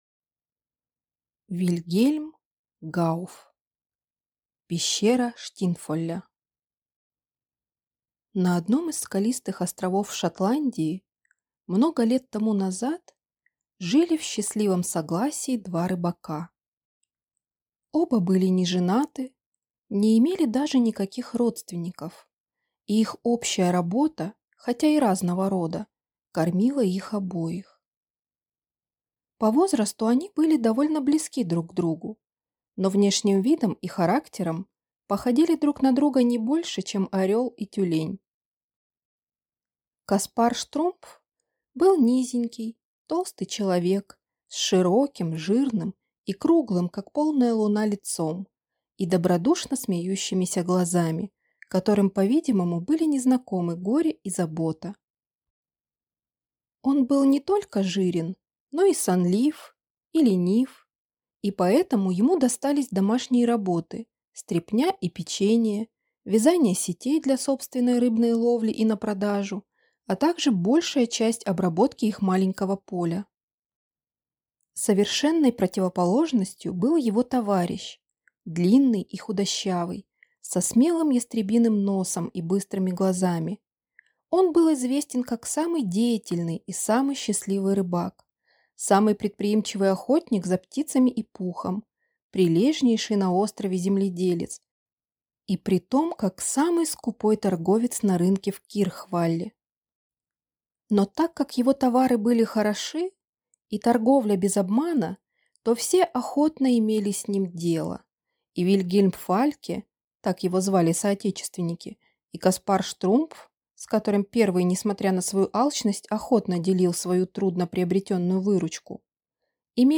Аудиокнига Пещера Штинфолля | Библиотека аудиокниг